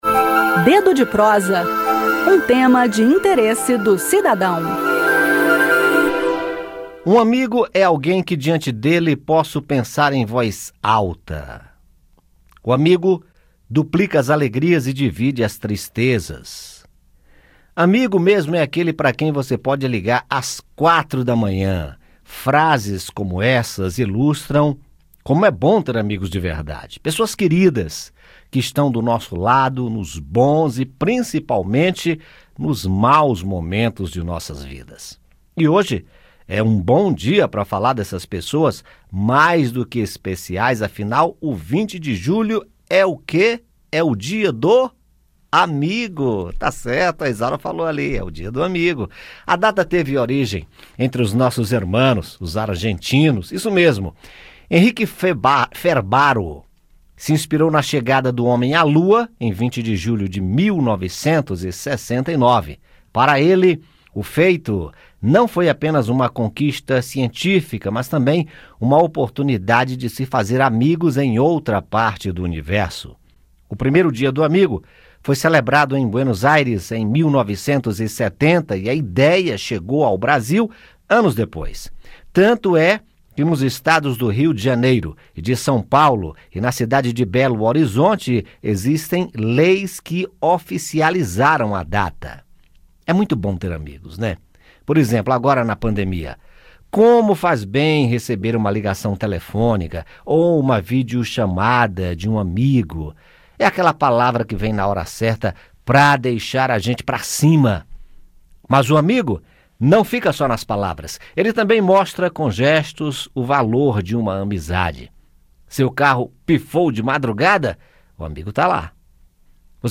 bate-papo